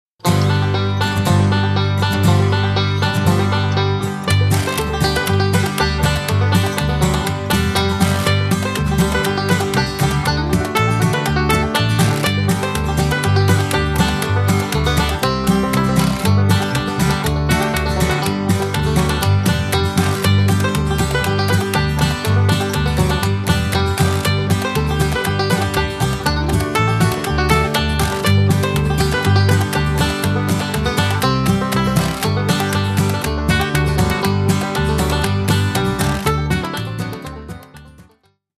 --folk-bluegrass music